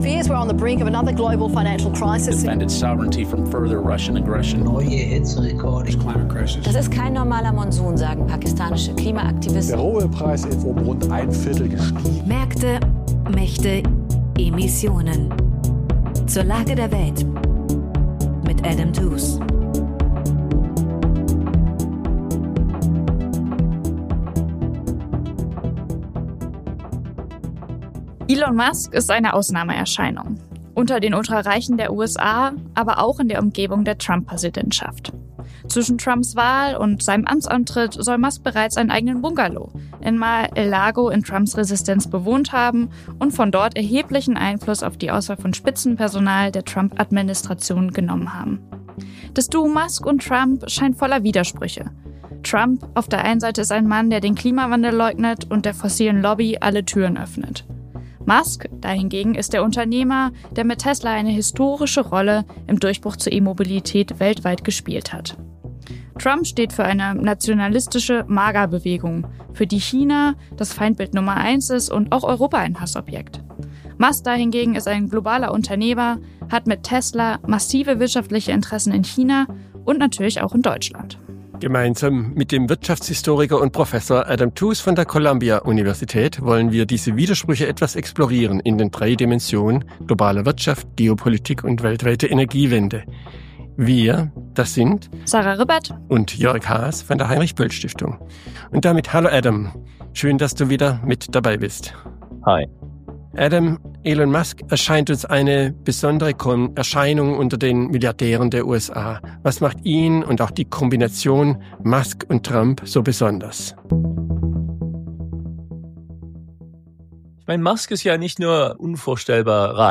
Mit dem prominenten Wirtschaftshistoriker Adam Tooze besprechen wir einmal im Monat die Krisen unserer Zeit an den Schnittstellen von Geopolitik, globaler Ökonomie und Klimakrise: Wie können Märkte, Mächte und die Klimakrise zusammen gedacht werden?